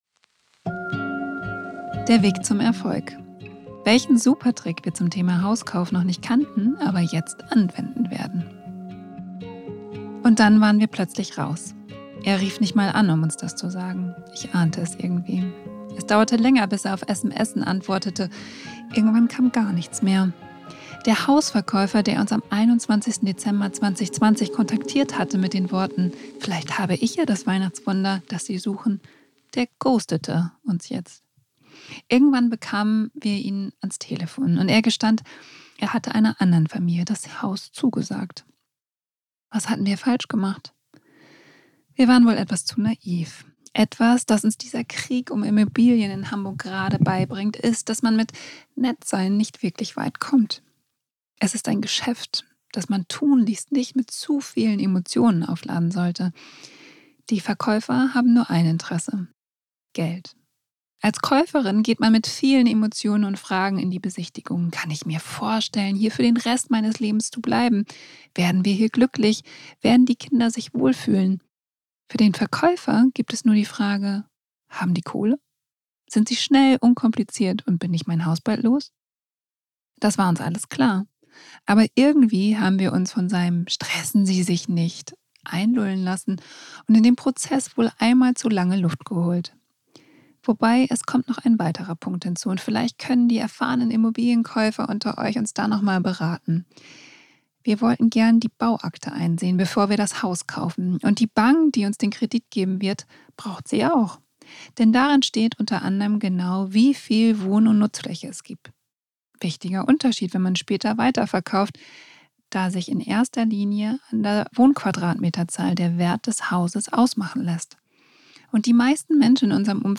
Audioartikel